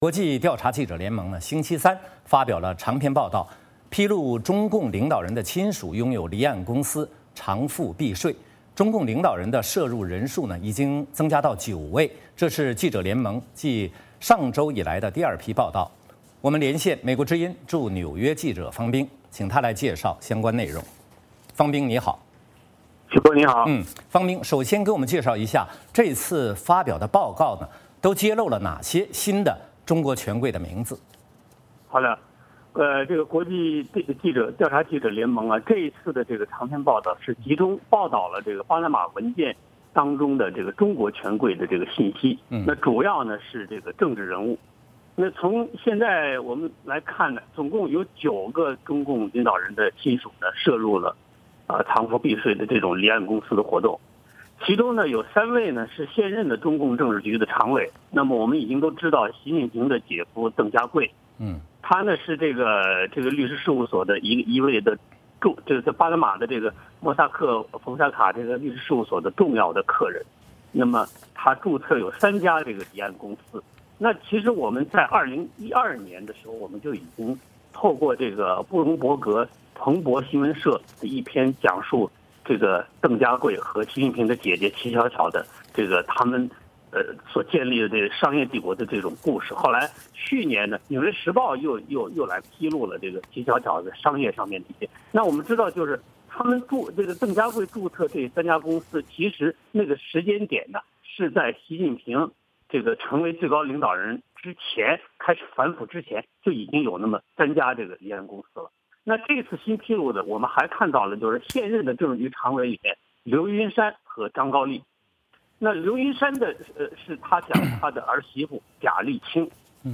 VOA连线：巴拿马文件继续发酵 涉及更多中共权贵家族